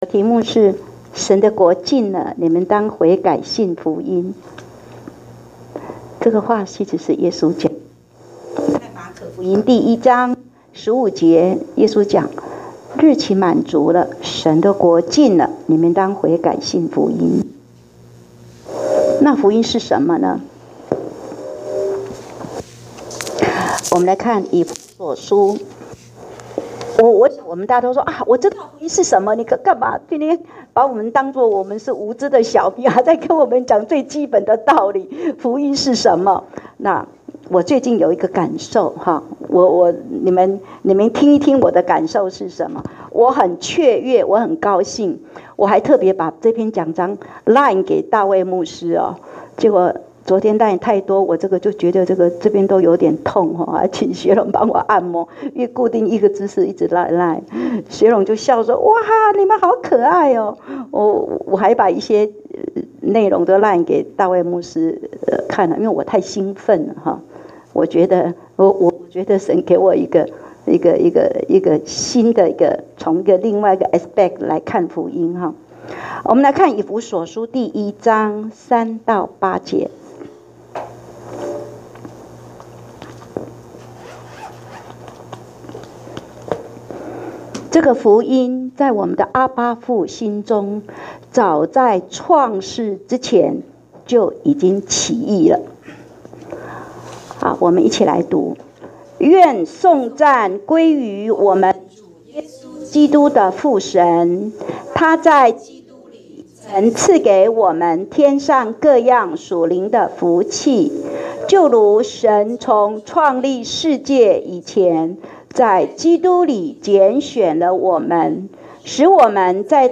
講道下載